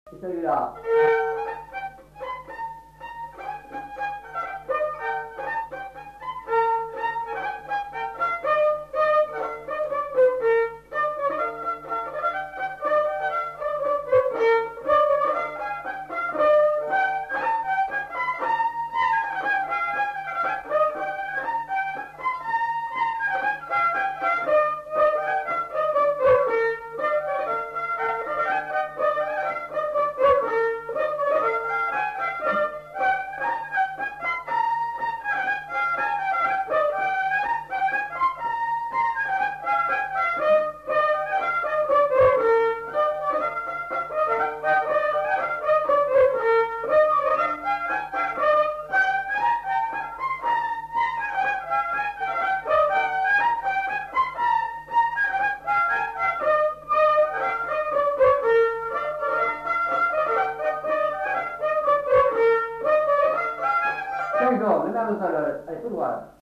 Aire culturelle : Lugues
Lieu : Casteljaloux
Genre : morceau instrumental
Instrument de musique : violon
Danse : rondeau